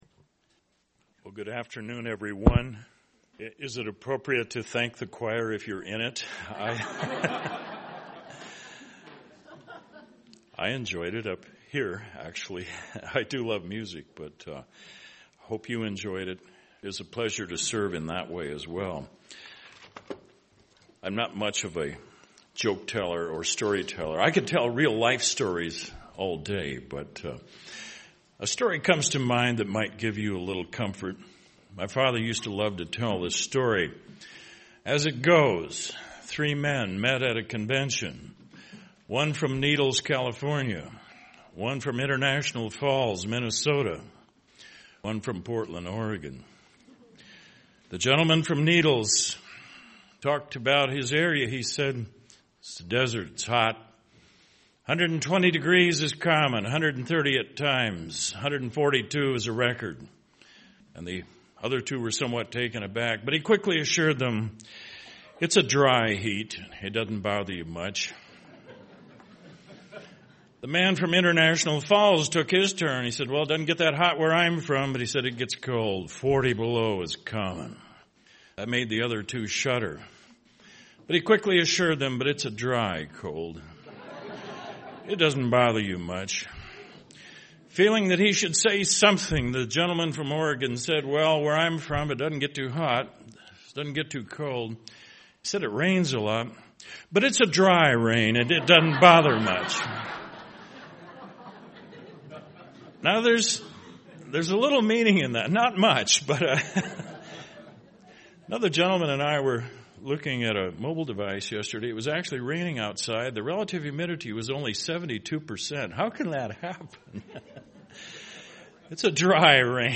This sermon was given at the Bigfork, Montana 2016 Feast site.